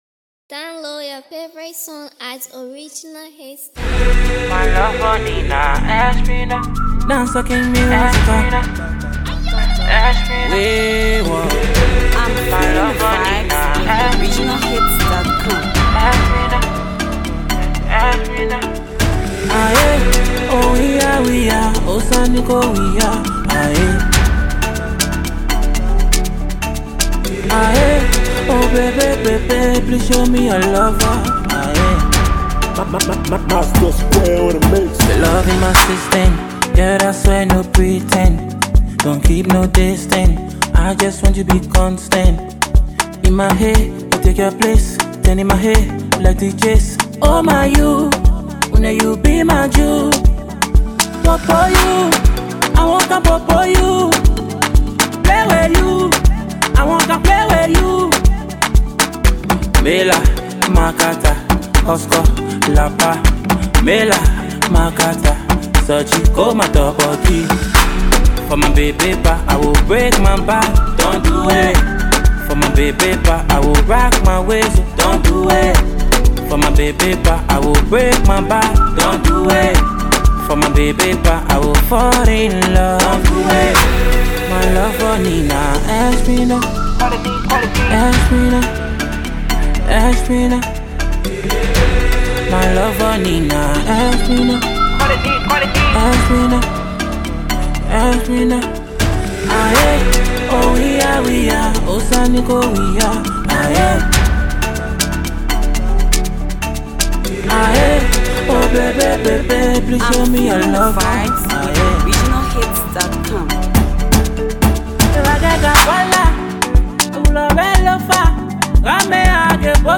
A nice piece melody banger
Download this love song and share your thoughts!!!